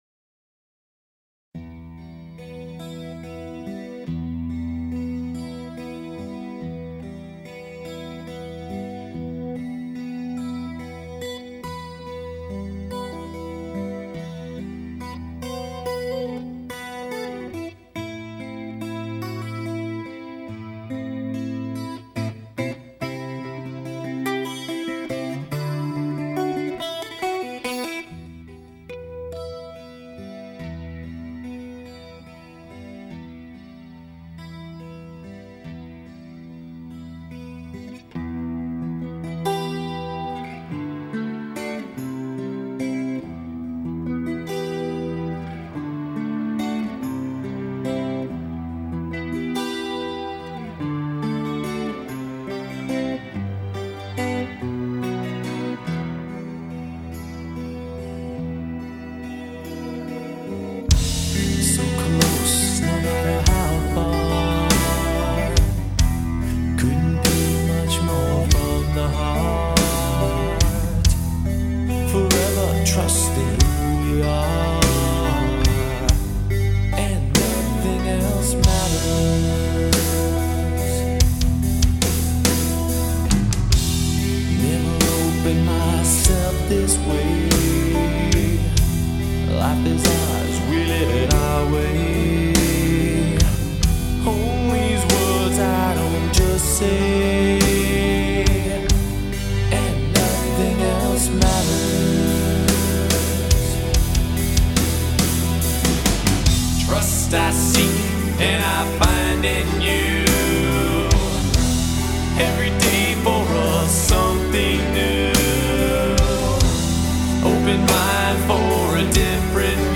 исполняющая музыку в стилях трэш-метал и хэви-метал